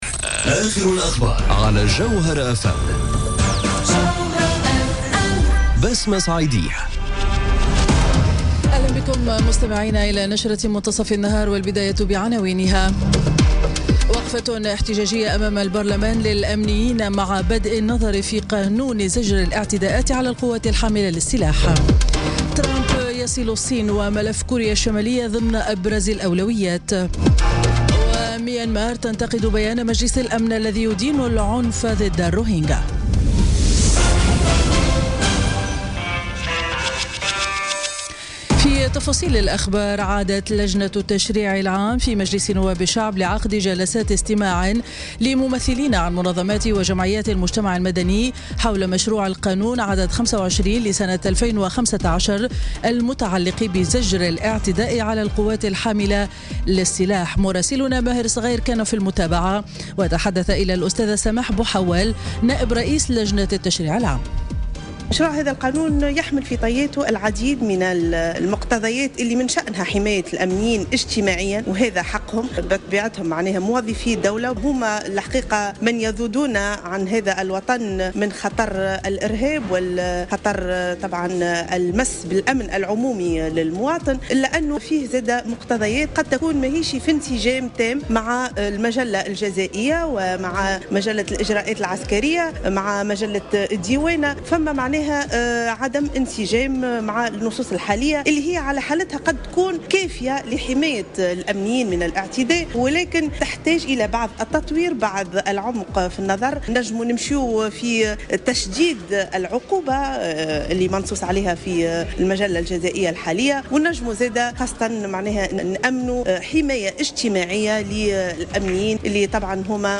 نشرة أخبار منتصف النهار ليوم الإربعاء 8 نوفمبر 2017